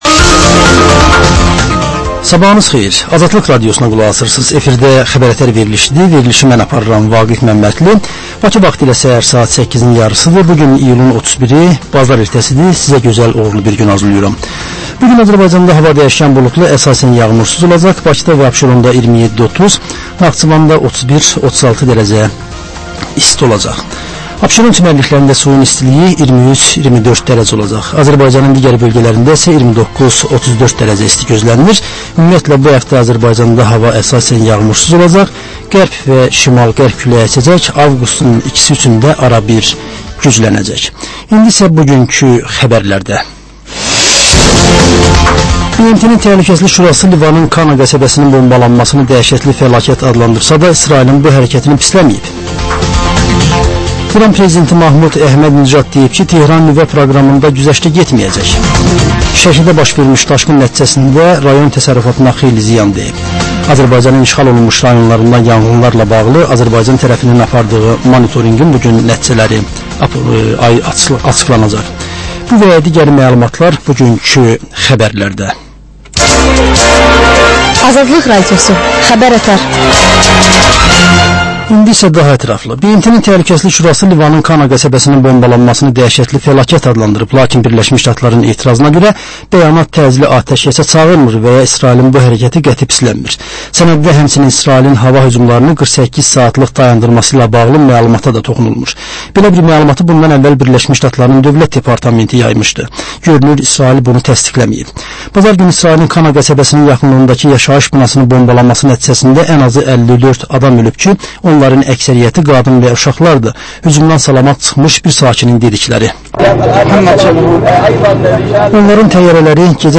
Səhər xəbərləri